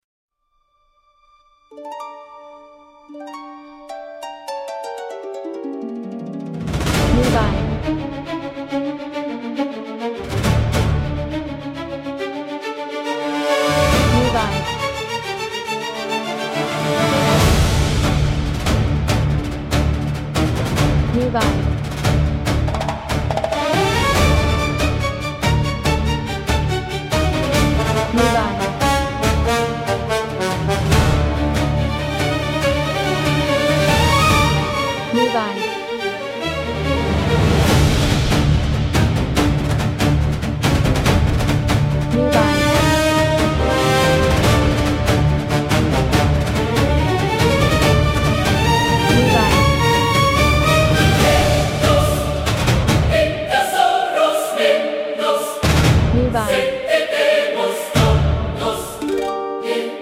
Genre: Epic cinematic